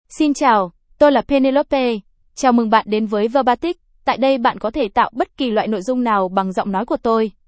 FemaleVietnamese (Vietnam)
PenelopeFemale Vietnamese AI voice
Penelope is a female AI voice for Vietnamese (Vietnam).
Voice sample
Female
Penelope delivers clear pronunciation with authentic Vietnam Vietnamese intonation, making your content sound professionally produced.